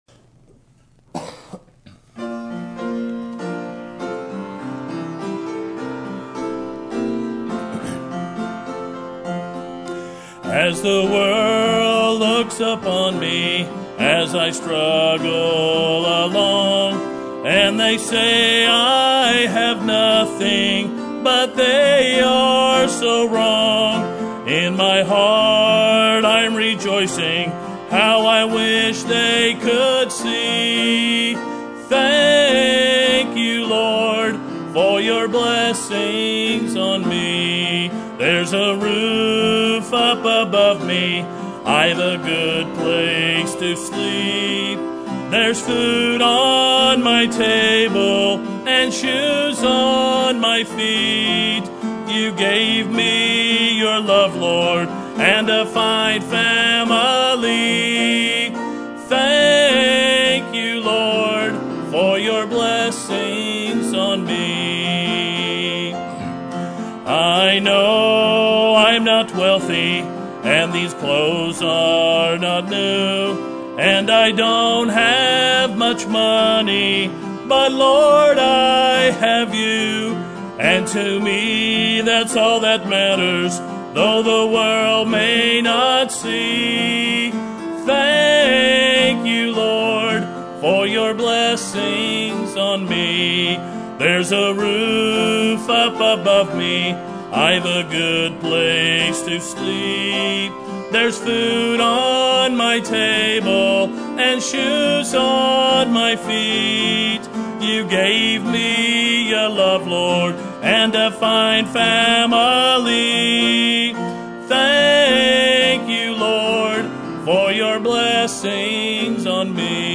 Sermon Topic: General Sermon Type: Service Sermon Audio: Sermon download: Download (31.2 MB) Sermon Tags: Jeremiah Stand Path Walk